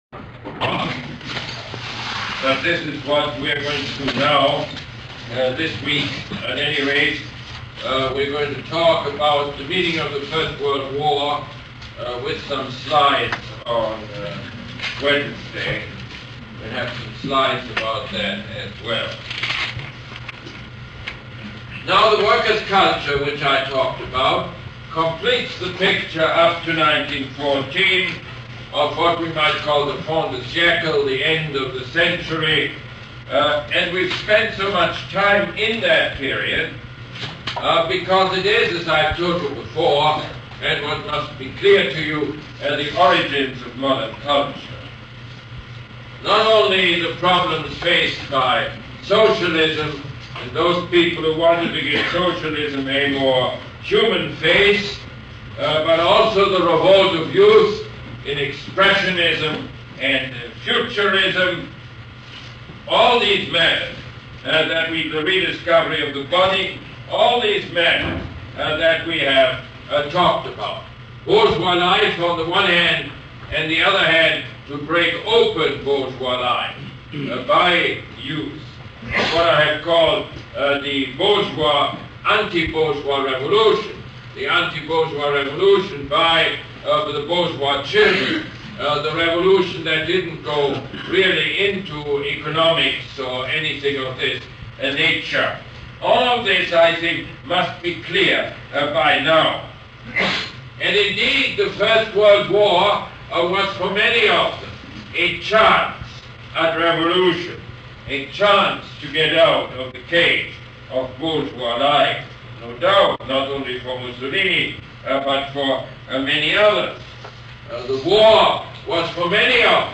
Lecture #18 - November 12, 1979